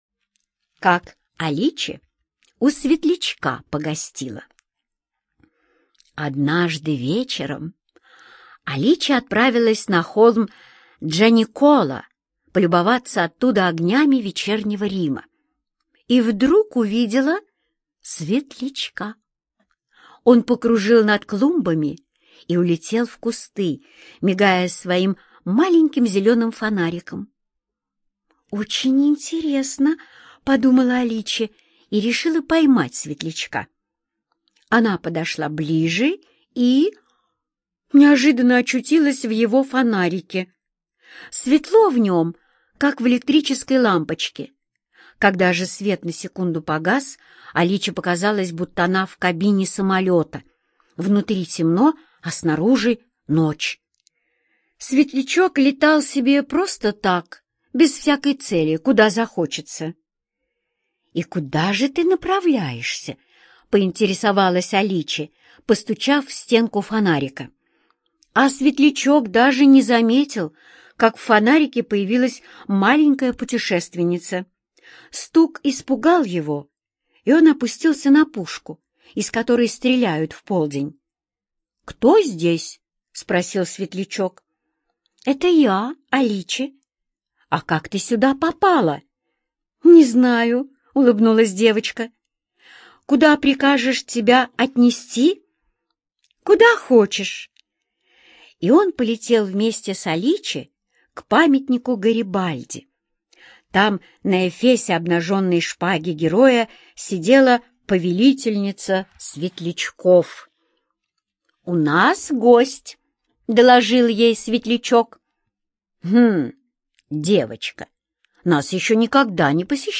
Слушать Как Аличе у светлячка погостила - аудиосказку Родари Д. Как крошечная и любознательная девочка Аличе подружилась со светлячками.